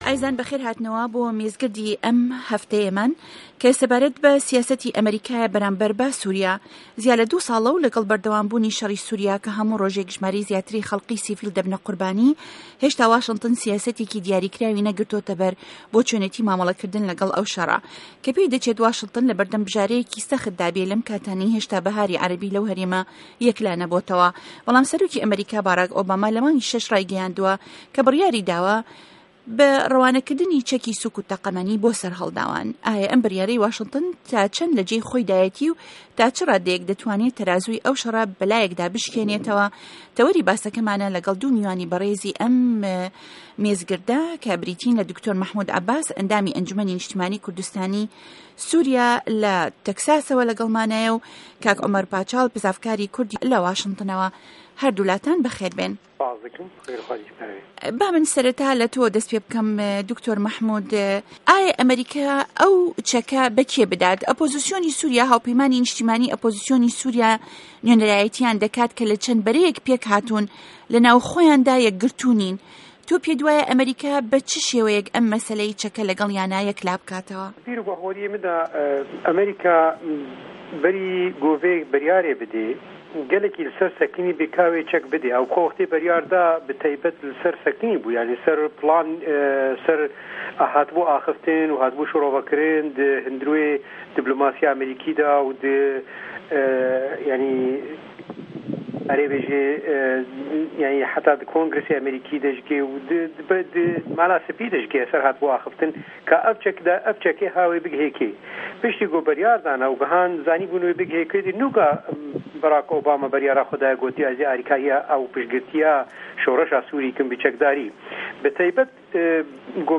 مێزگردێك سه‌باره‌ت به‌ سیاسه‌تی ئه‌مه‌ریکا به‌رامبه‌ر سوریا